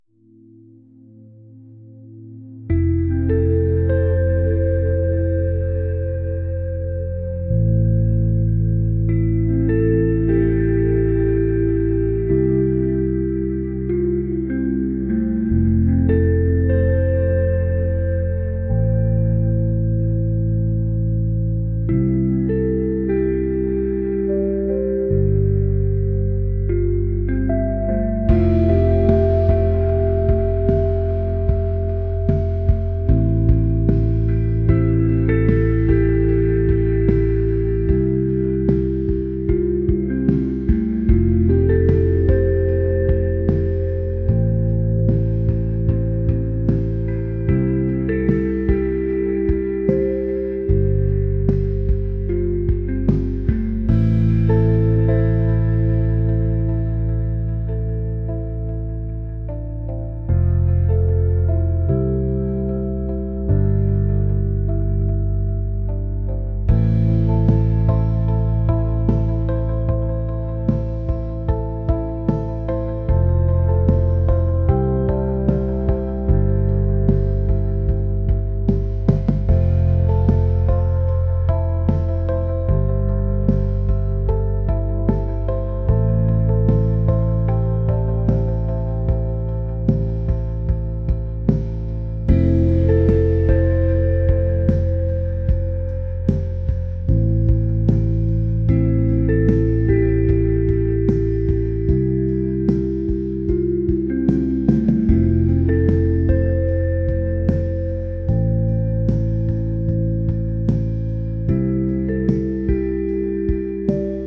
pop | atmospheric | ethereal